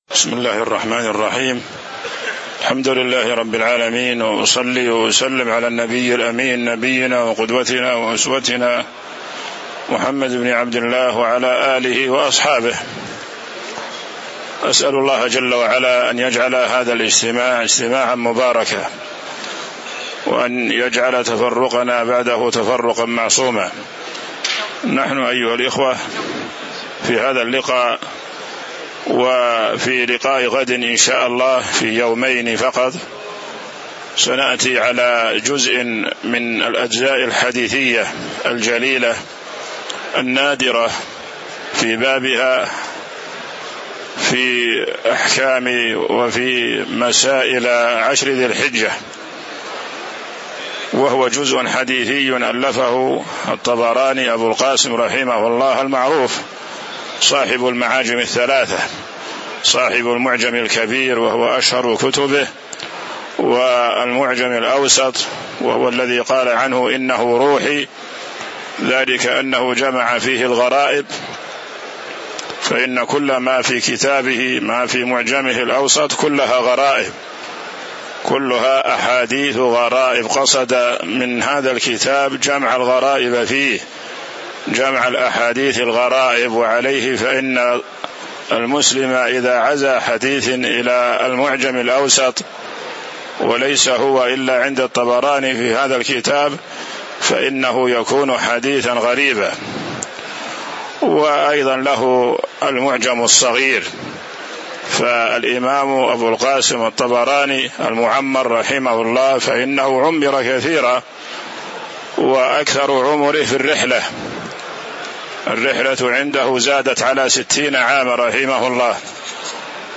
تاريخ النشر ٢٨ ذو القعدة ١٤٤٦ هـ المكان: المسجد النبوي الشيخ